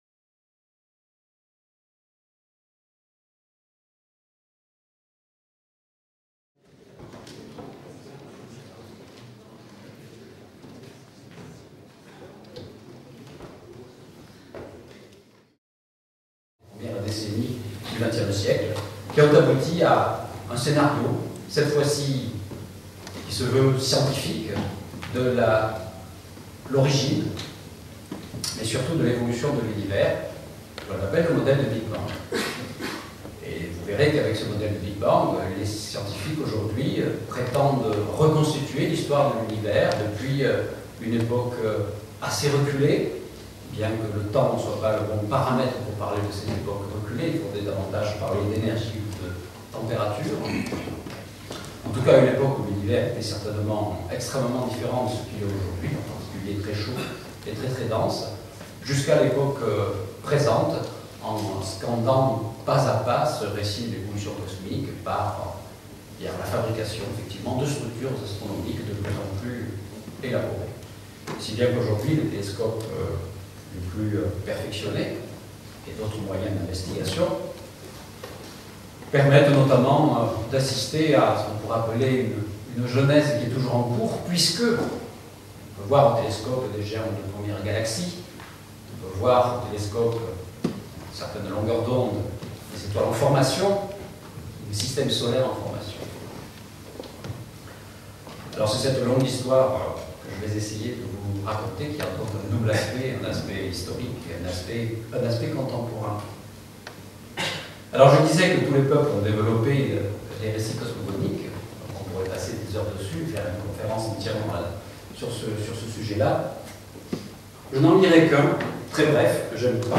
Une conférence donnée par Jean-Pierre Luminet (astrophysicien), dans le cadre des conférences publiques de l'IAP. La qualité de l'image et du son de cette captation vidéo n'est pas aussi bonne qu'elle pourrait l'être si elle était réalisée aujourd'hui, mais l'intérêt qu'elle présente pour l'histoire de la diffusion des connaissances mérite sa présentation ici, à titre d'archive.